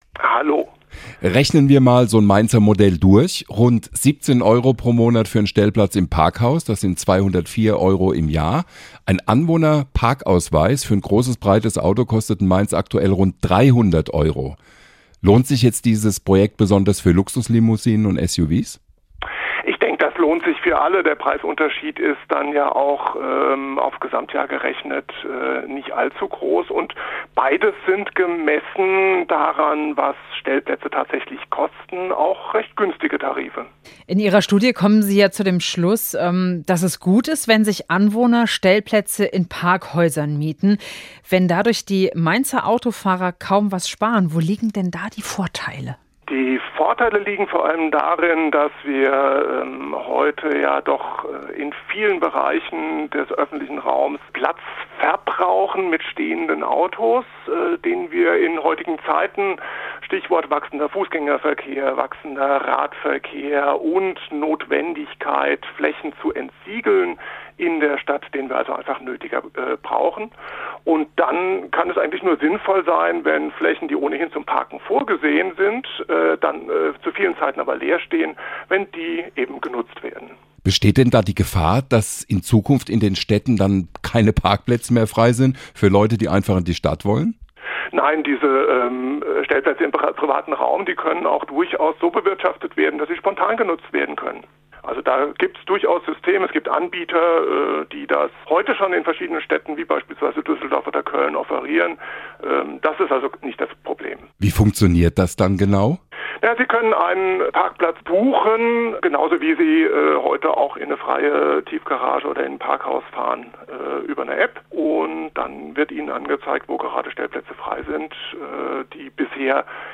SWR1 Interviews